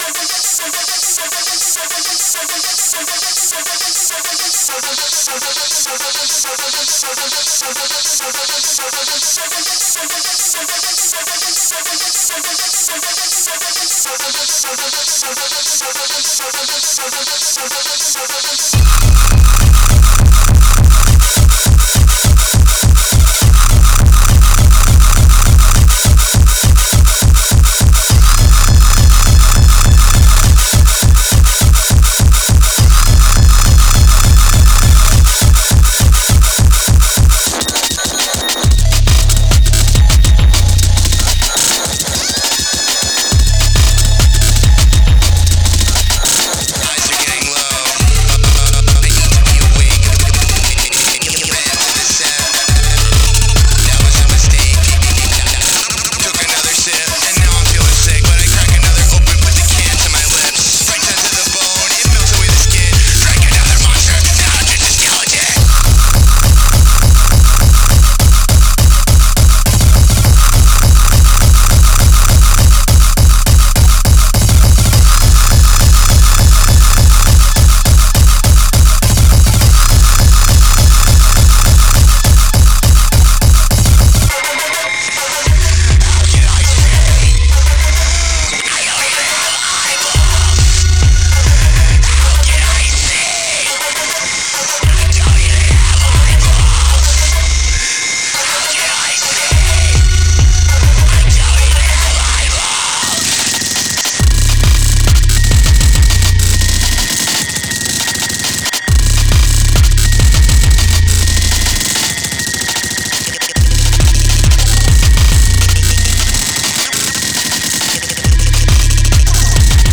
noise, gabber, digital hardcore, breakcore, ,